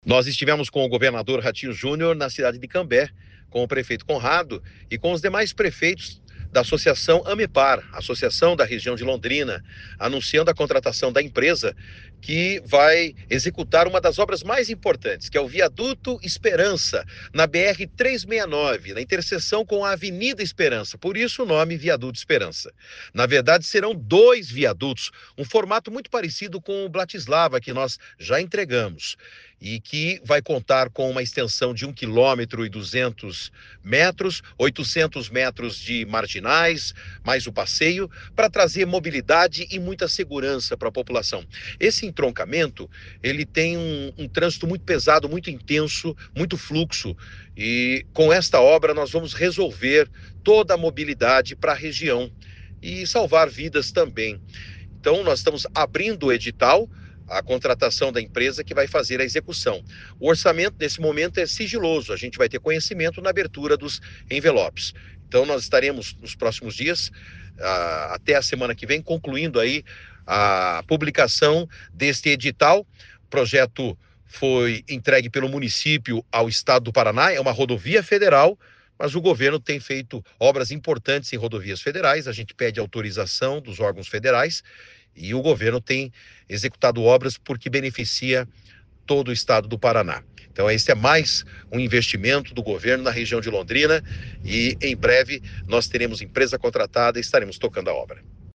Sonora do secretário de Infraestrutura e Logística, Sandro Alex, sobre o edital da construção do Viaduto da Esperança